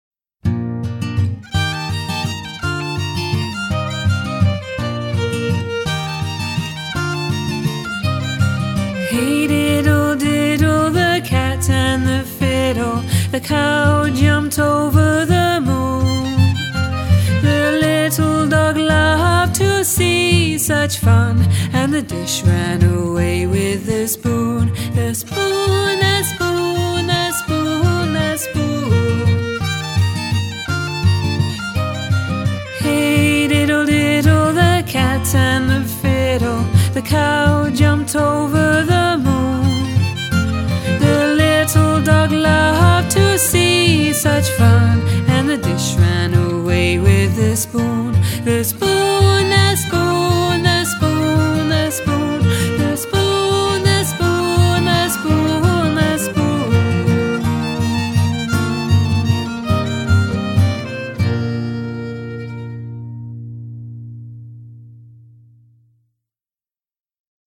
TraditionalFolk
fiddle
a warm, folky arrangement